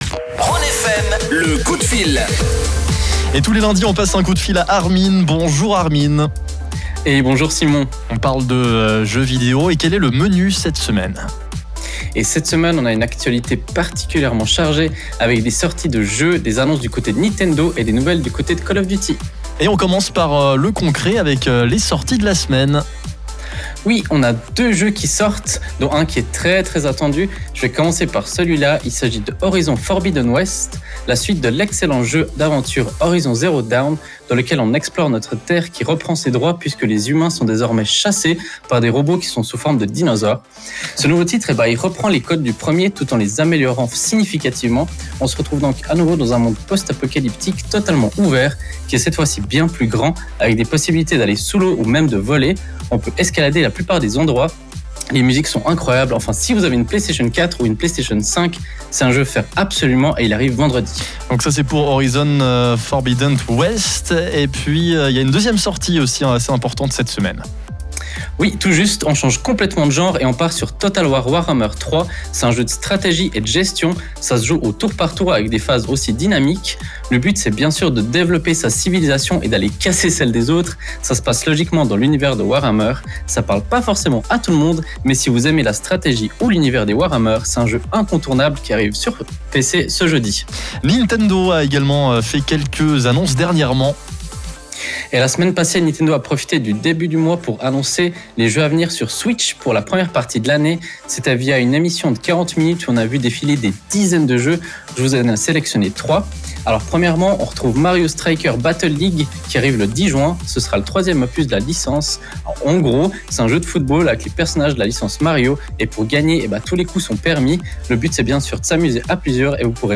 C’est lundi et comme tous les débuts de semaine, aux alentours de 17h20, on vous propose notre petite chronique gaming sur la radio Rhône FM. Aujourd’hui, au programme, deux sorties majeures, quelques annonces Nintendo et le futur de Call of Duty.